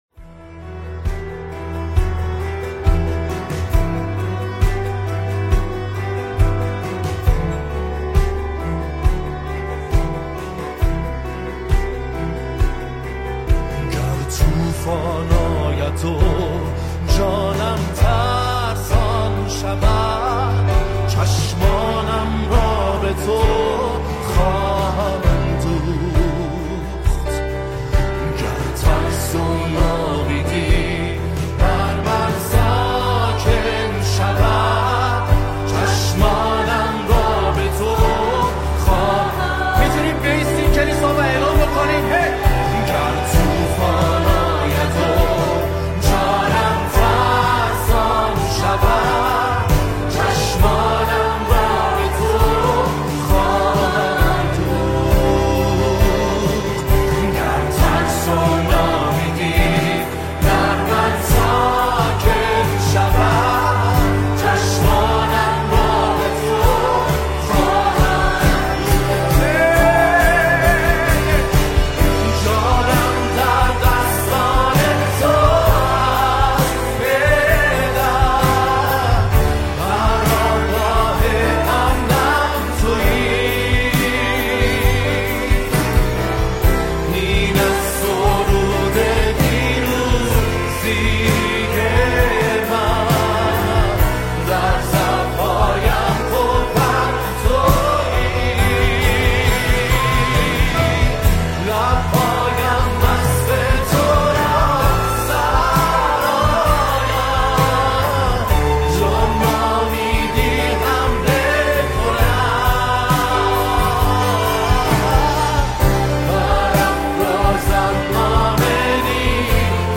مینور